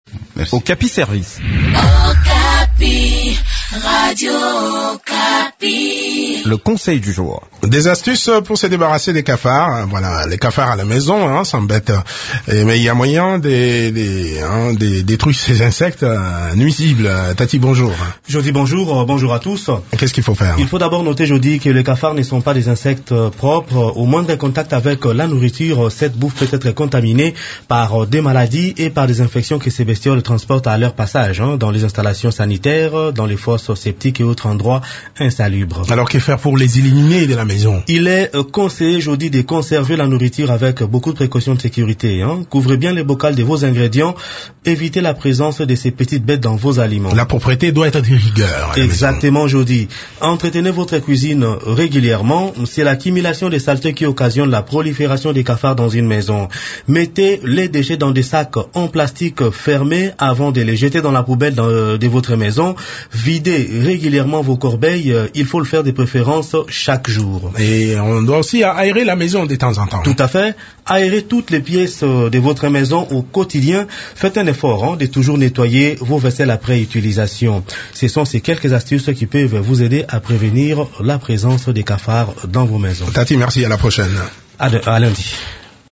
Découvrez quelques astuces qui peuvent vous aider à vous débarrasser des cafards dans cette chronique